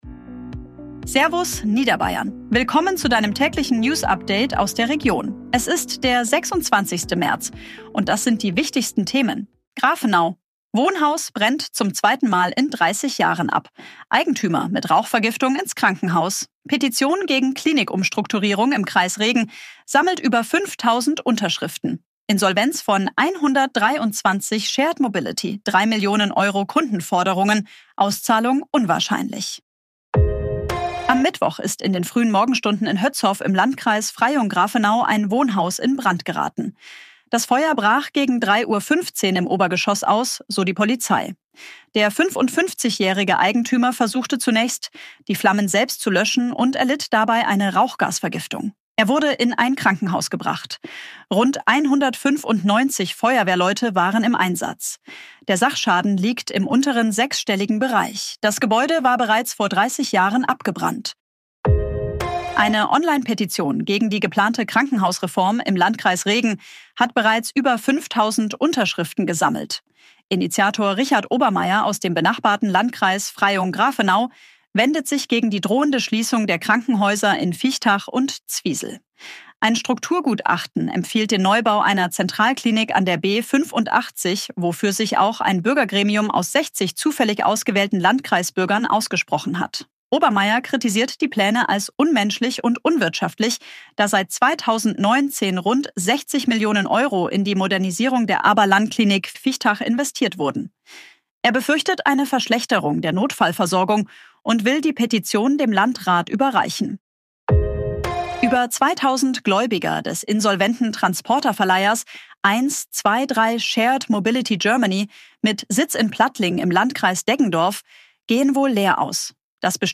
Tägliche Nachrichten aus deiner Region
Nachrichten , Gesellschaft & Kultur